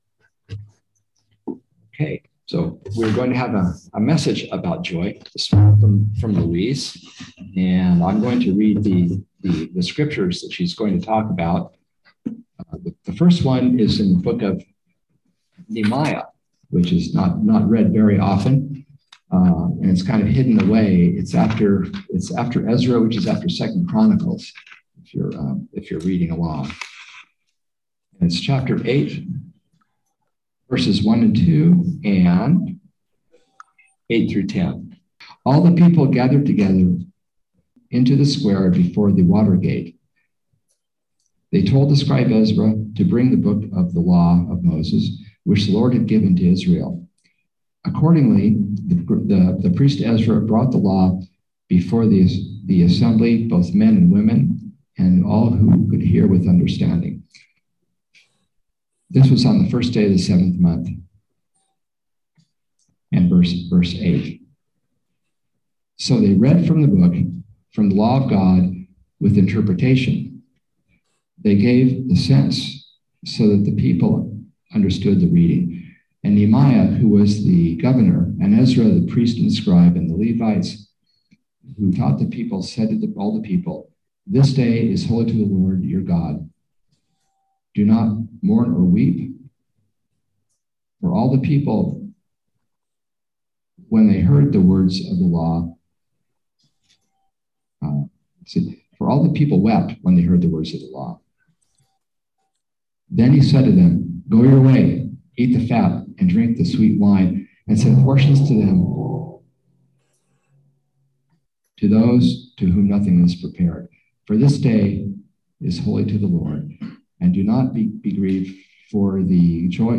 Listen to the most recent message from Sunday worship at Berkeley Friends Church, “Joy.”